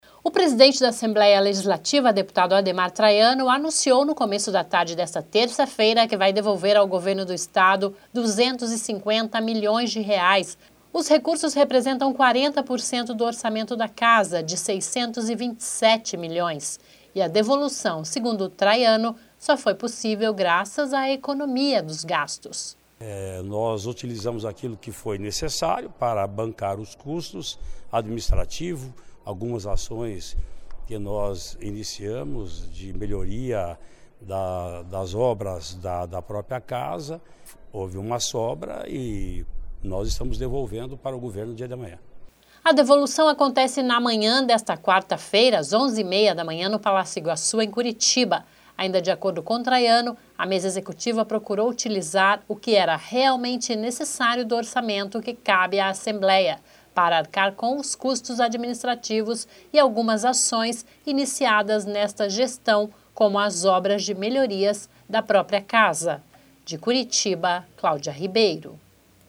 (Sonora)